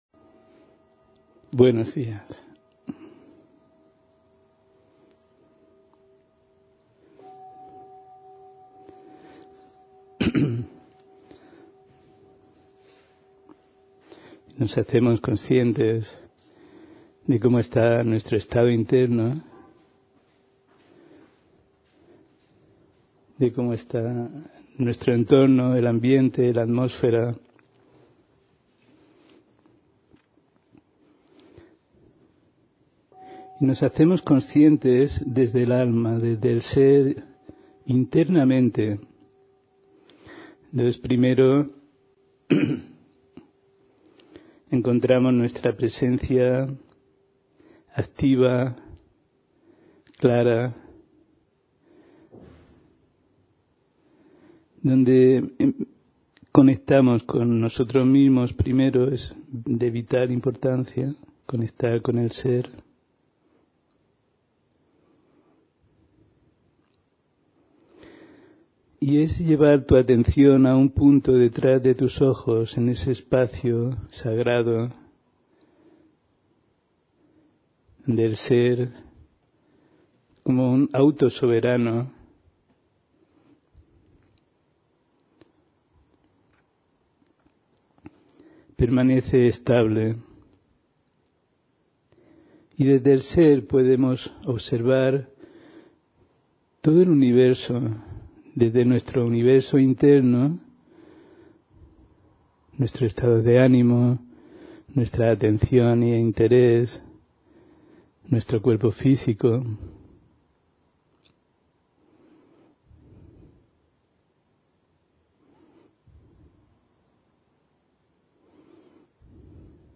Meditación de la Mañana: Regreso a la fuente (25 Mayo 2020)